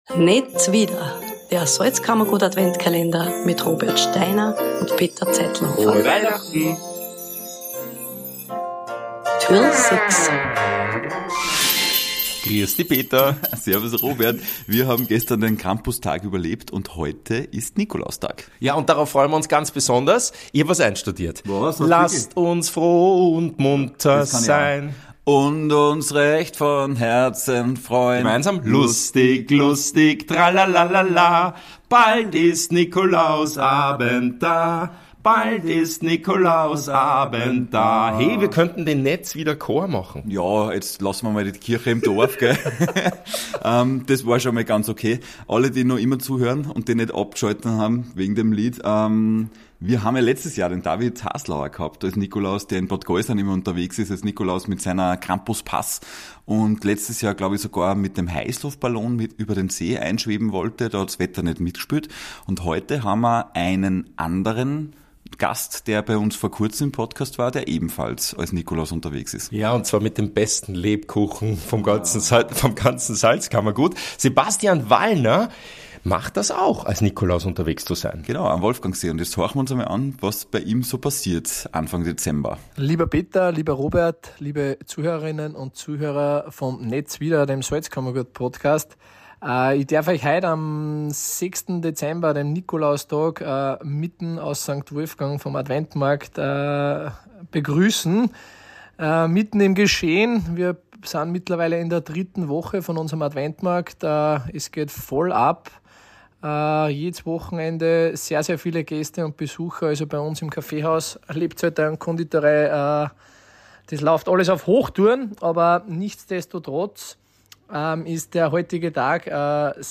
Am Nikolaustag haben wir natürlich den Nikolaus zu Gast bei uns im